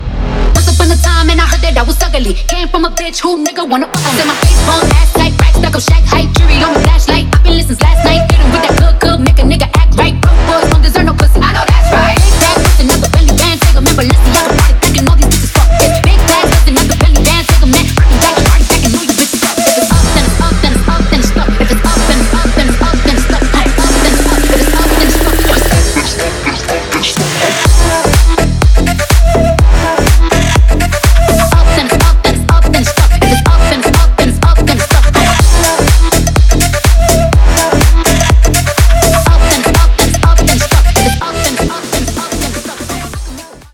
громкие
Club House
pop-rap
клубная музыка